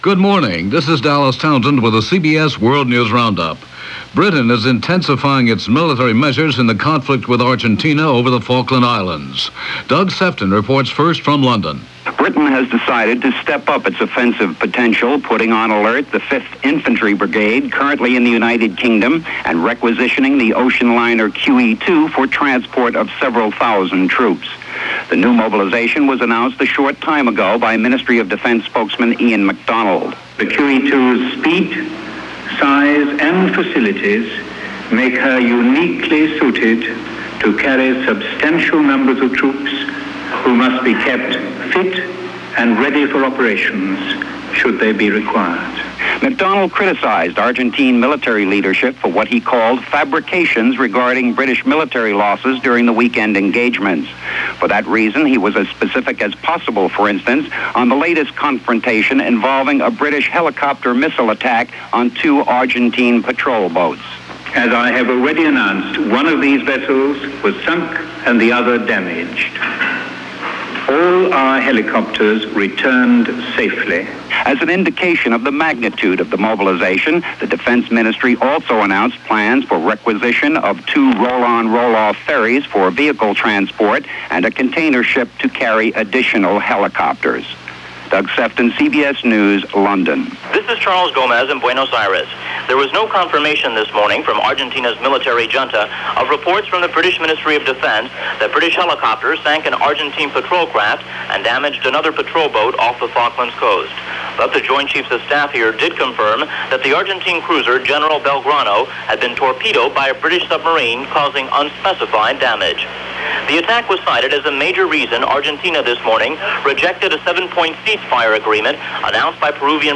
9:00 am news